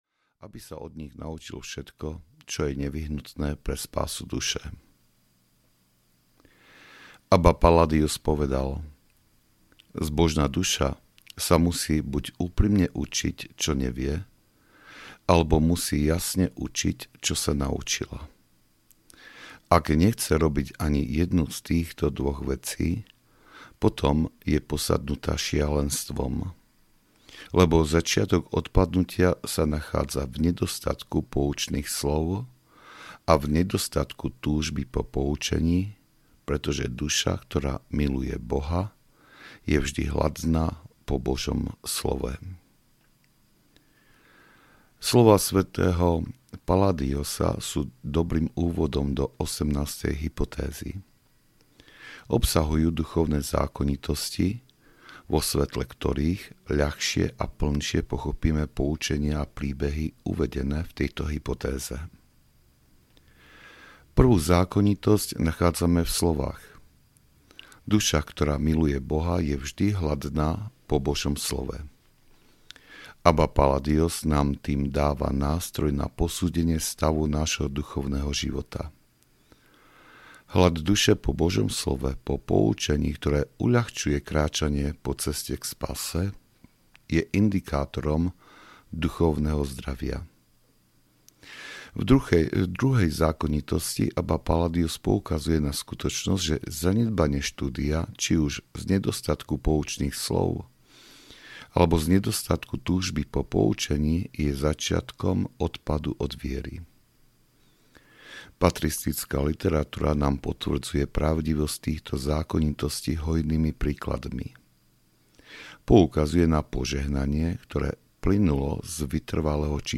EVERGETINOS 3 - Výroky púštnych otcov audiokniha
Ukázka z knihy